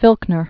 (fĭlknər)